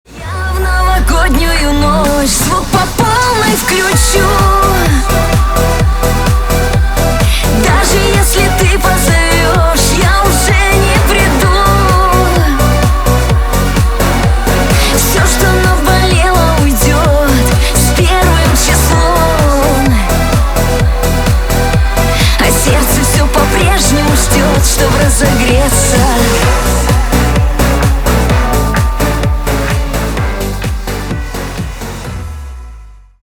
• Качество: 320, Stereo
праздничные